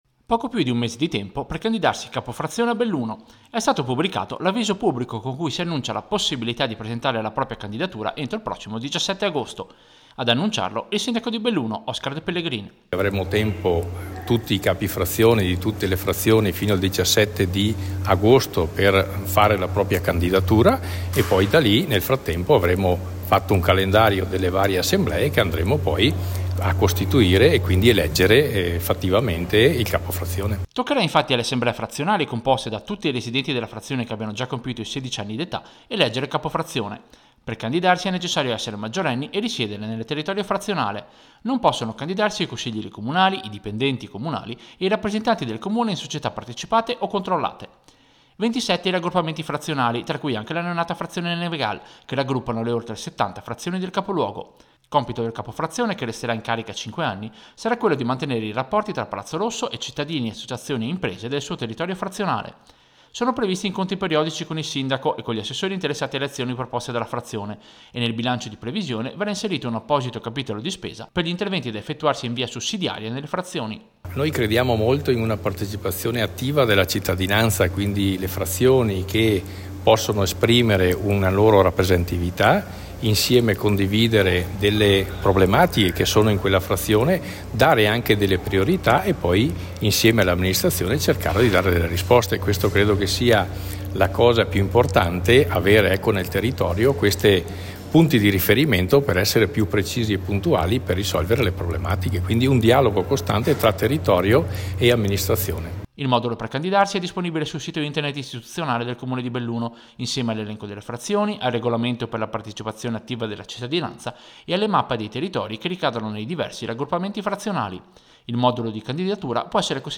Servizio-Candidature-capifrazione-Belluno.mp3